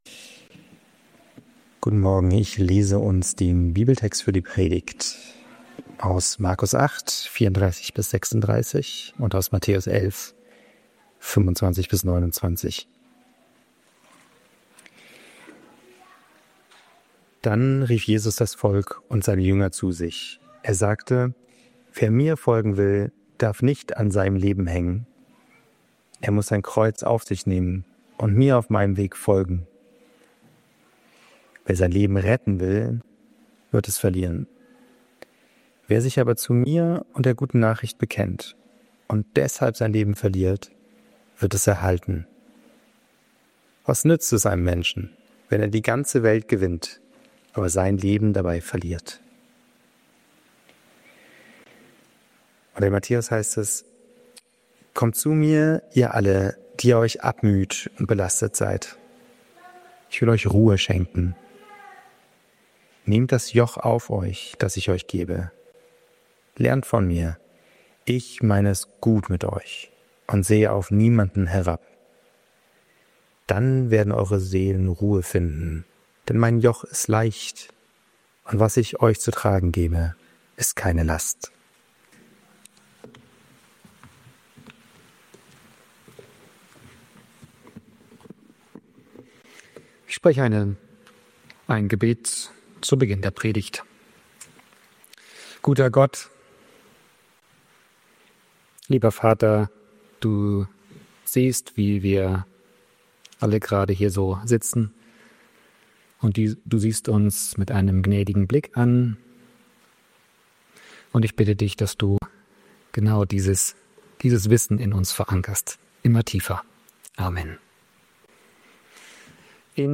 Der Wert von Veränderung ~ Berlinprojekt Predigten Podcast